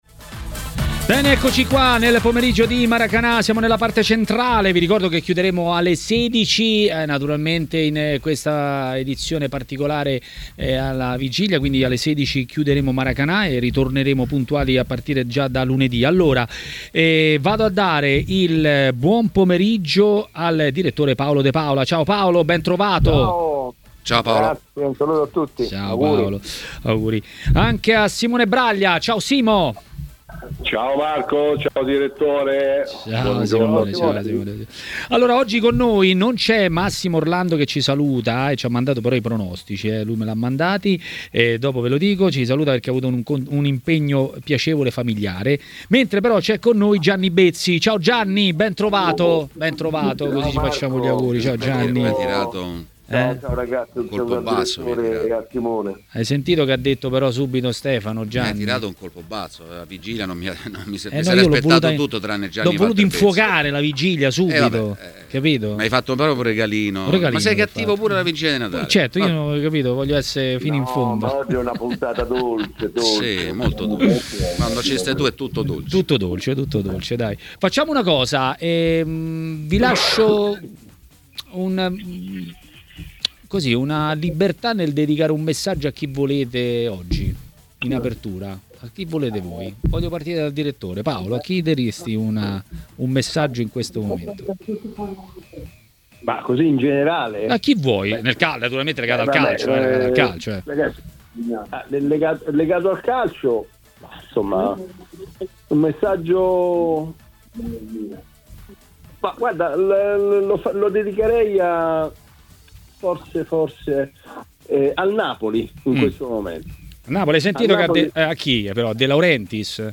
A commentare le notizie del giorno a Maracanà, trasmissione di TMW Radio